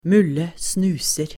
b2_marsvinet_10.mp3 Information on how to embed this object is missing.
Mulle snuser.